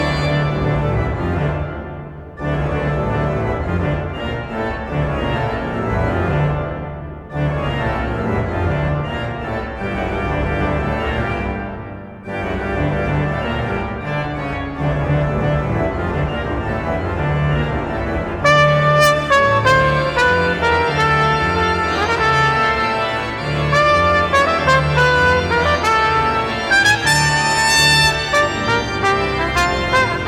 Trompete und Flügelhorn
Orgel und Klavier
Percussion